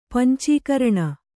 ♪ pancīkaraṇa